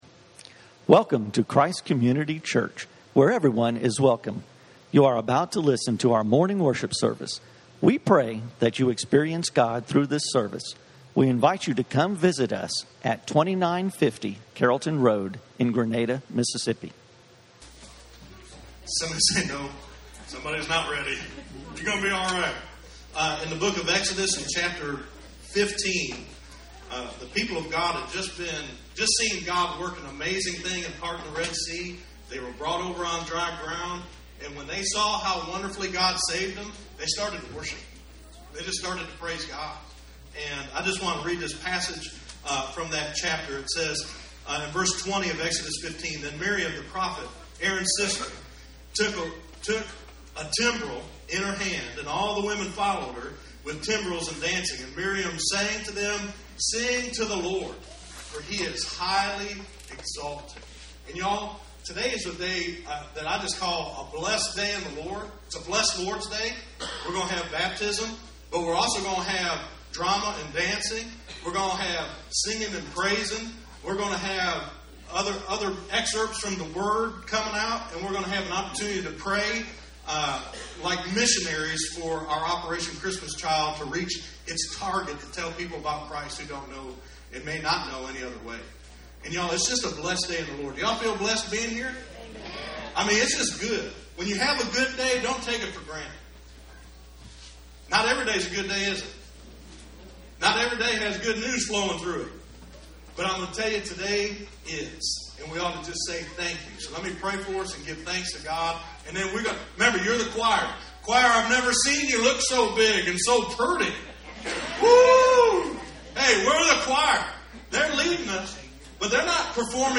Baptism Service